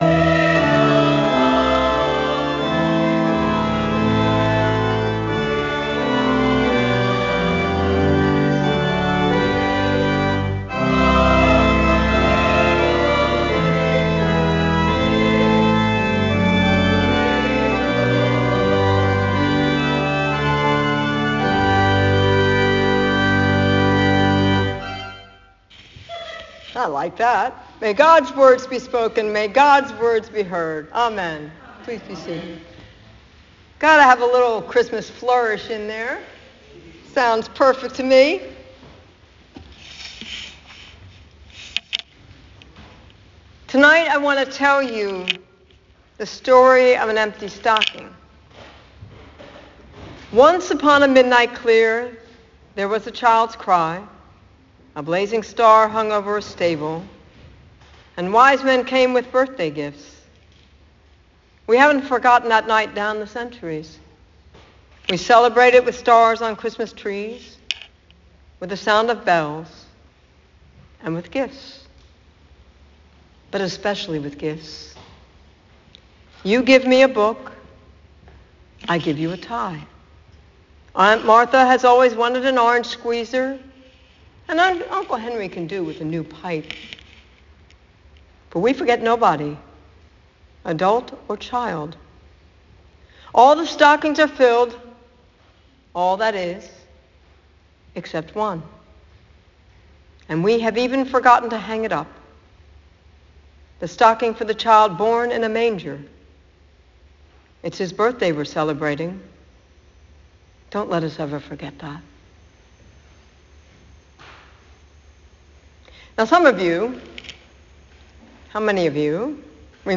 Rec-001-Sermon-XMas_Eve-11pm.m4a